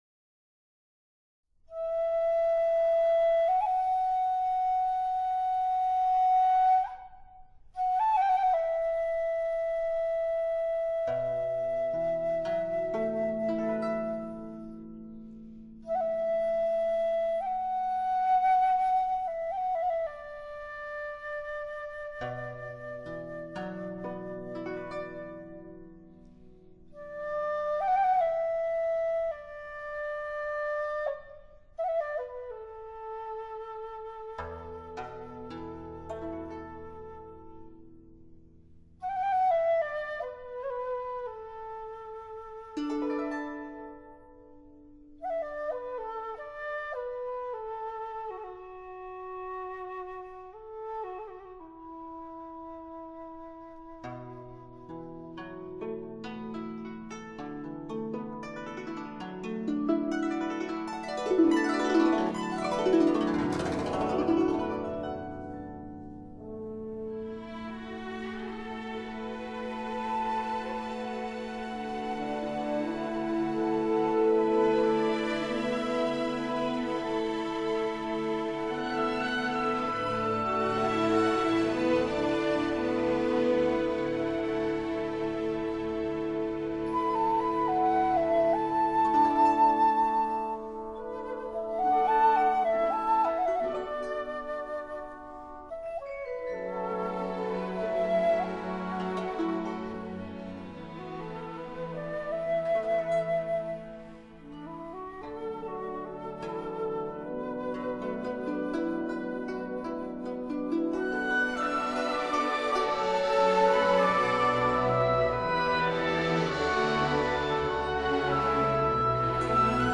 这些作品全是以现代作曲技法为主要的创作技巧,其中有些稍为前卫。
洞箫
古筝